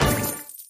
Appear_Small_Total_Win_Sound.mp3